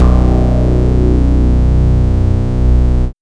TRANCE ACT-R.wav